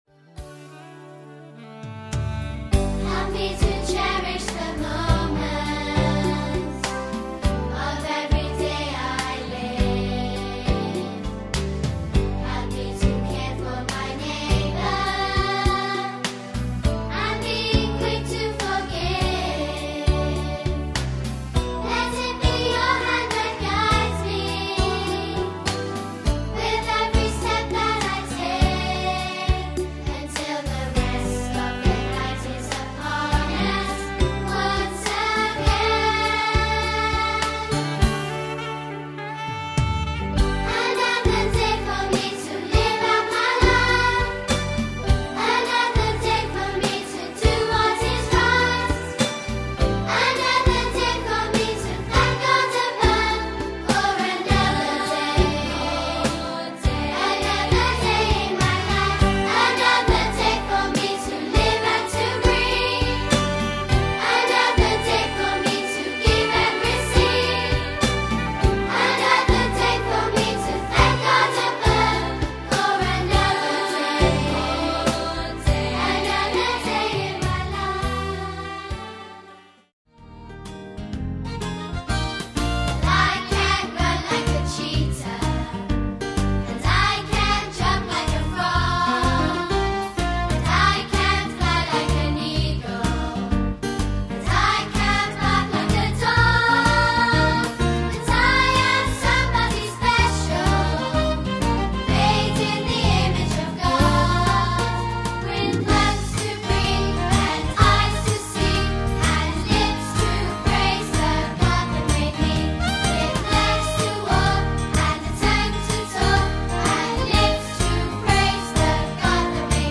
A collection of 15 assembly praise songs.